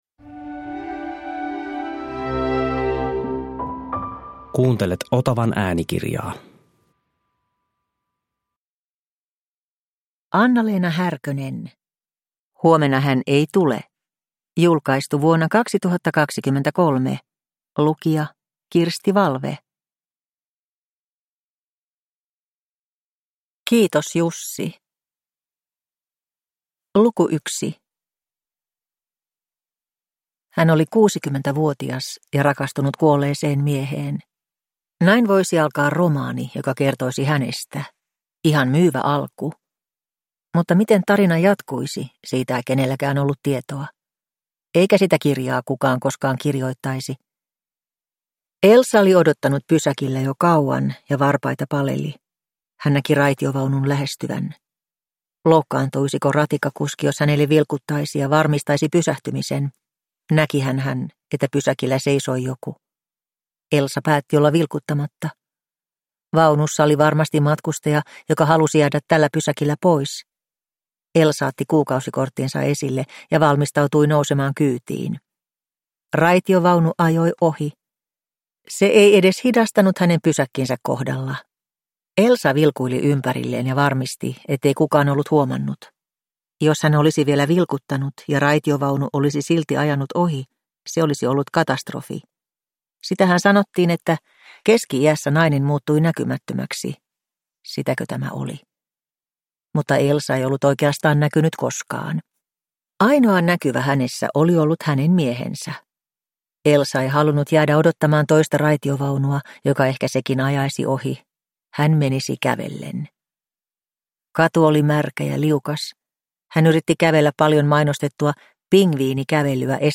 Huomenna hän ei tule – Ljudbok – Laddas ner